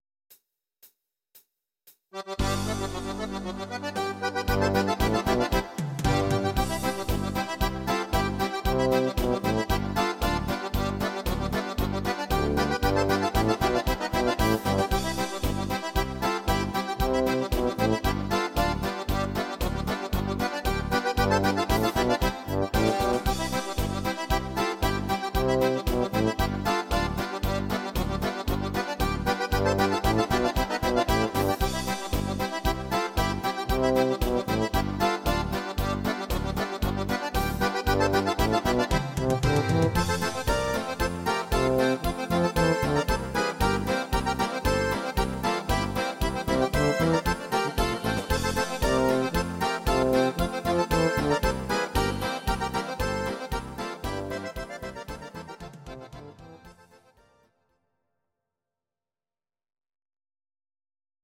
These are MP3 versions of our MIDI file catalogue.
Please note: no vocals and no karaoke included.
instr. Akkordeon